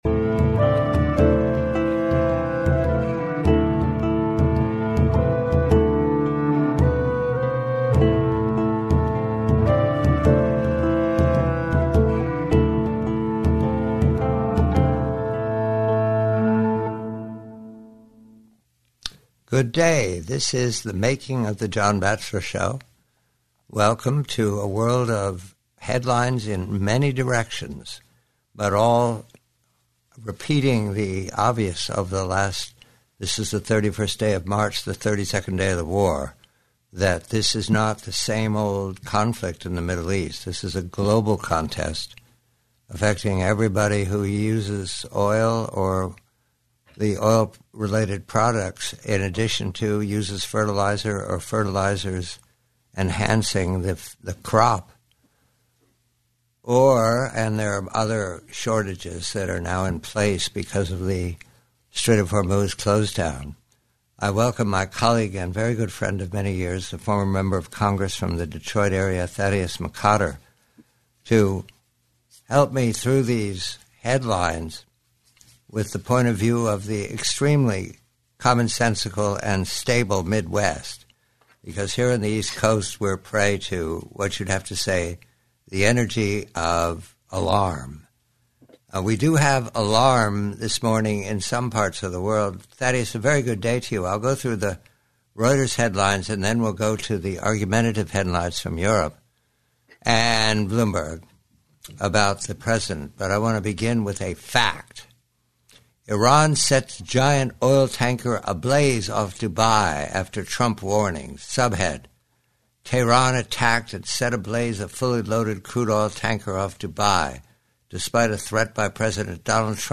This broadcast transcript features a dialogue between John Batchelor and Thaddaeus McCotter regarding a series of interconnected global crises involving Iran, Russia, and Ukraine.